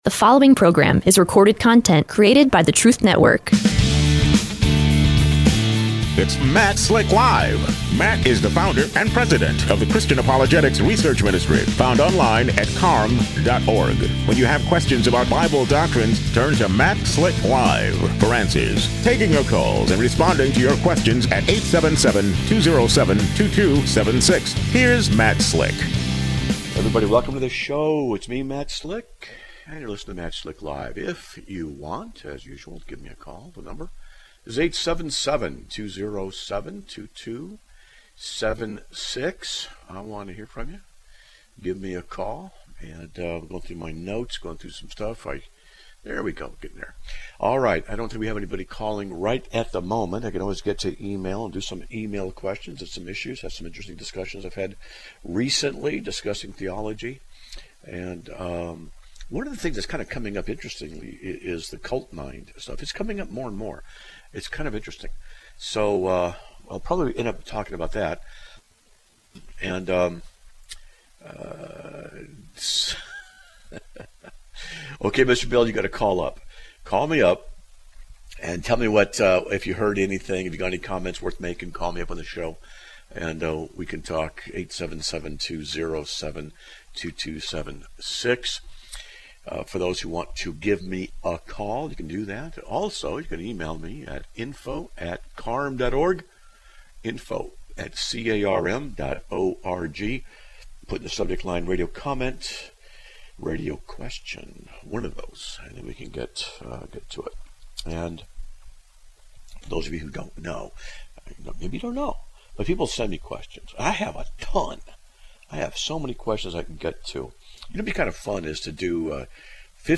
Answers will be discussed in a future show.